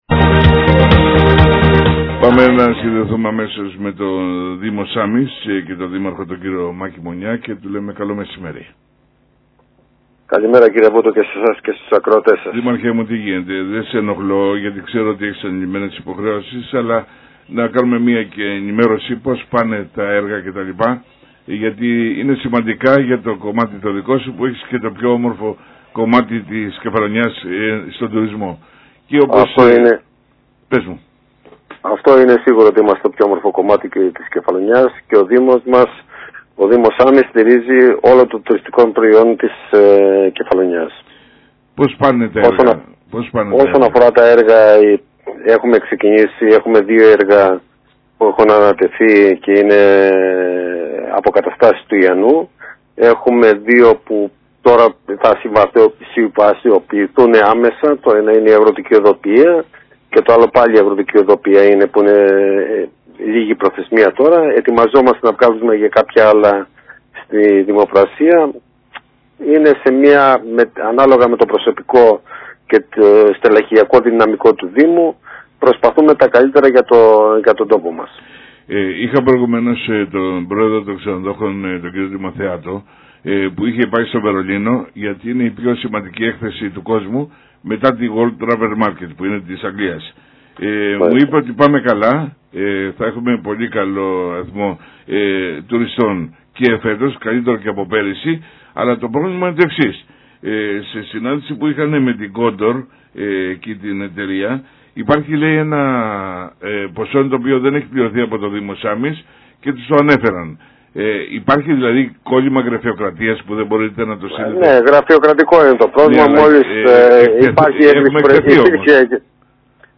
Ας Ακούσουμε τι μου είπε στην Εκπομπή μου και στο Νησί 93,9 fm ο Δήμαρχος Σάμης κ Μονιας Μακης